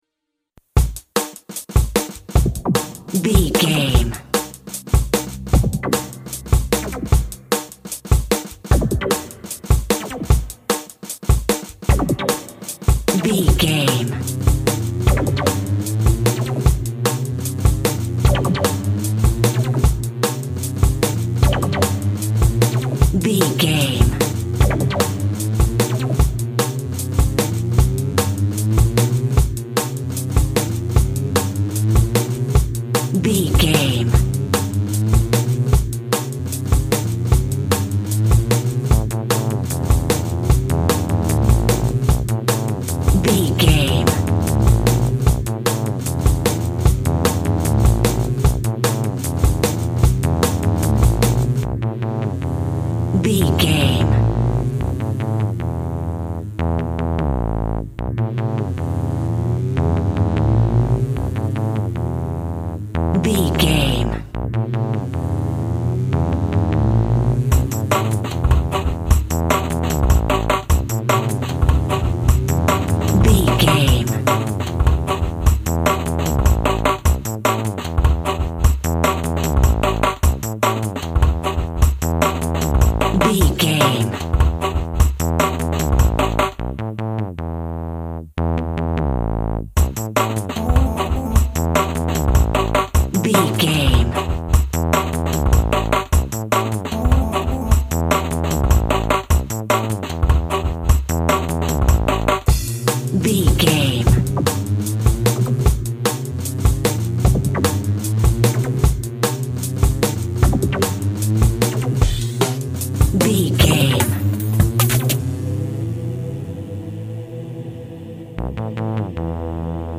Acoustic Drum & Bass.
Ionian/Major
Fast
futuristic
hypnotic
industrial
frantic
powerful
drums
synthesiser
nu jazz
downtempo
synth lead
synth bass